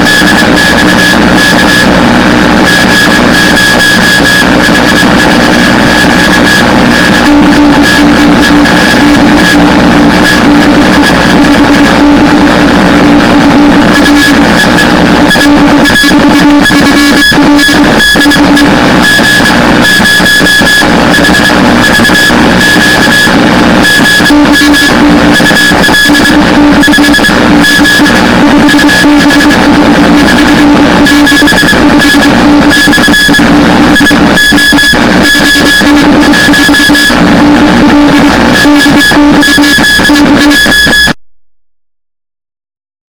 50 MHz DX SOUND FILES
The recordings themselves were made with an old cassette tape recorder located below one of the two outdated Labtec sound-blaster speakers I use as externals.